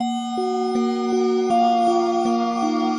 Sizzle - 160 BPM_Master.wav